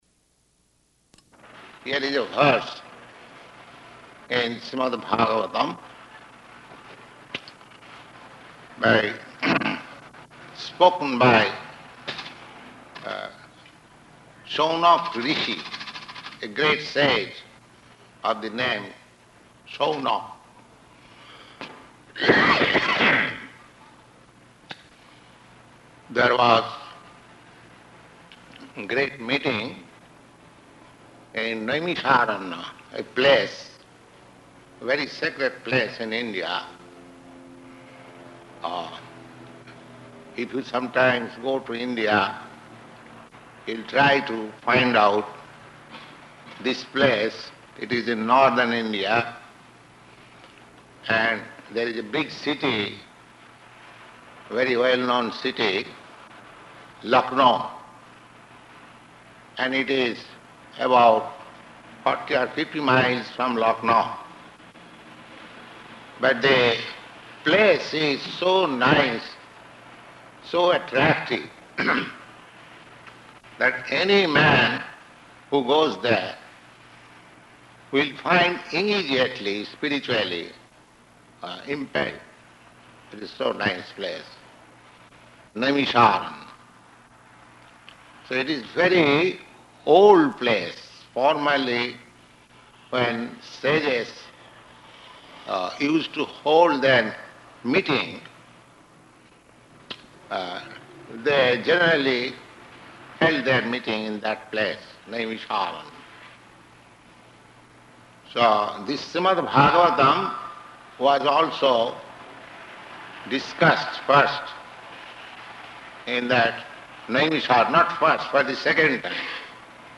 Śrīmad-Bhāgavatam Lecture